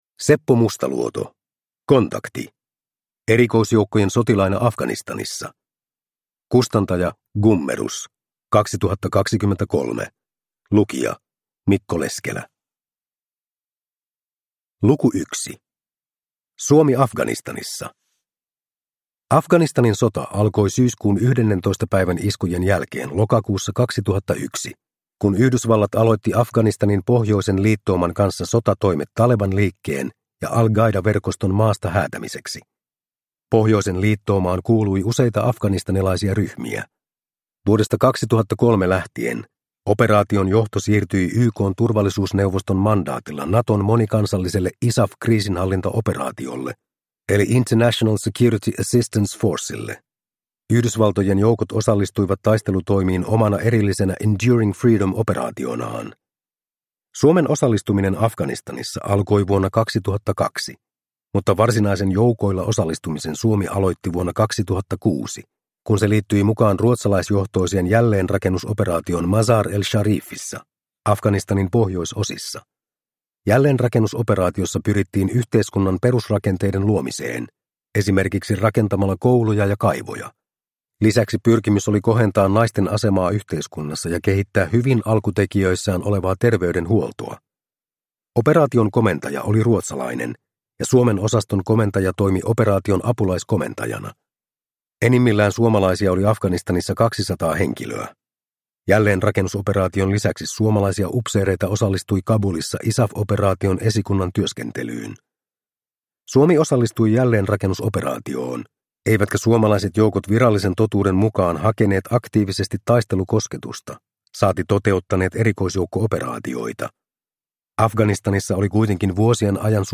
Kontakti! – Ljudbok – Laddas ner